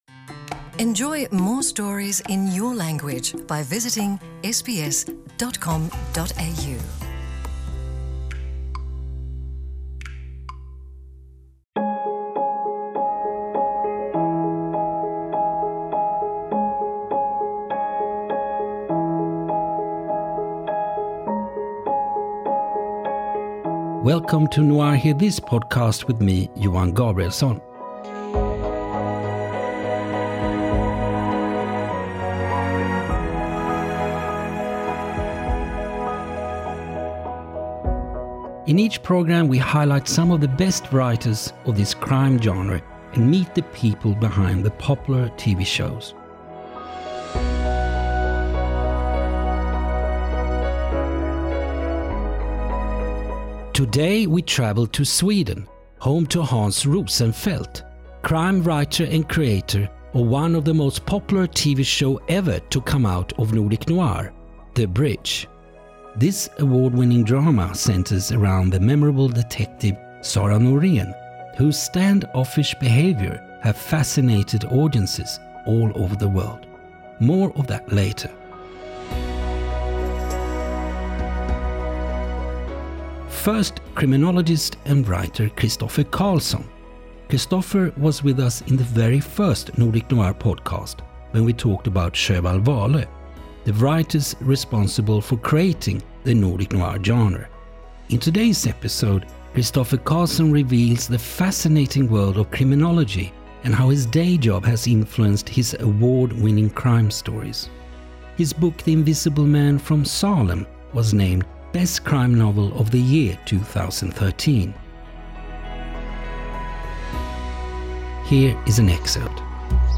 This episode's featured book reading is of Christoffer Carlsson’s book The Invisible Man from Salem (Scribe Publications, 2015)